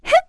Erze-Vox_Jump_kr.wav